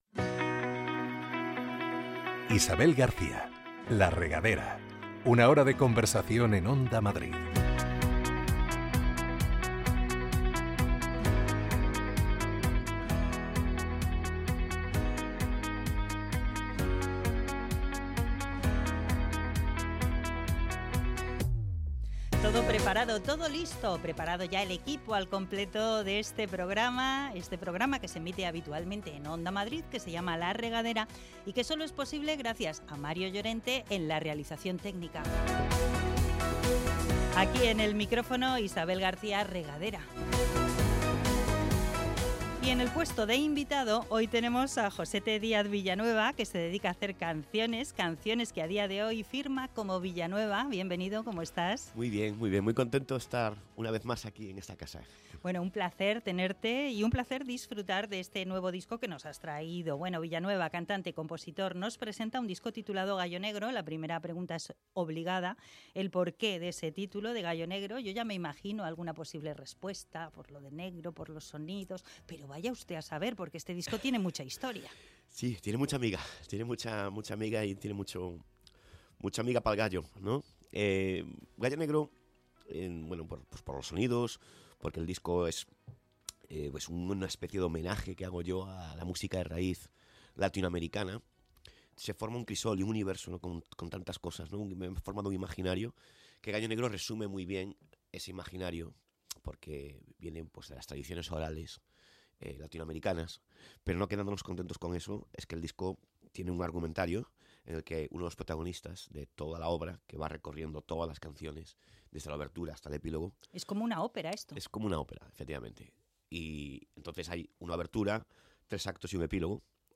Un espacio para conversar, con buena música de fondo y conocer en profundidad a todo tipo de personajes interesantes y populares.